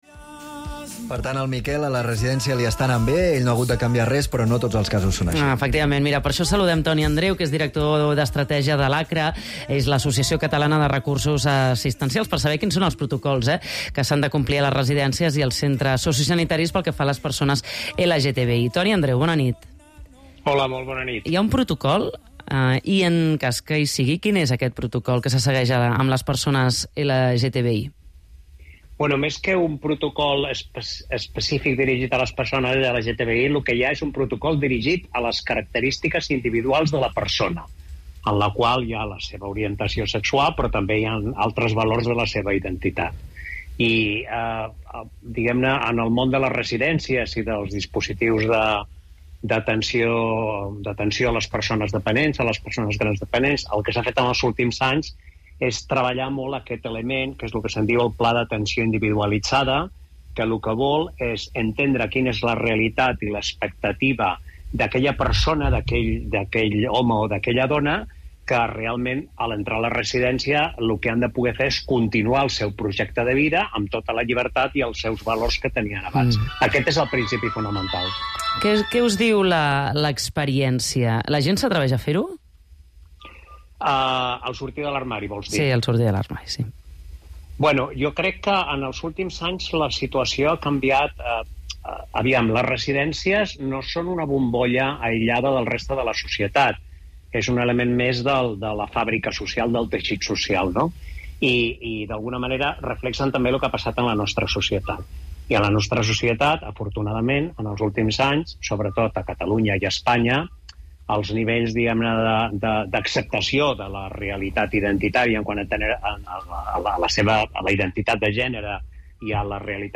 ha participat en una entrevista telefònica en el programa de ràdio Catalunya Nit per parlar sobre la Guia Pràctica per incorporar la perspectiva LGBTI+ a les residències i altres centres i recursos per a les persones grans.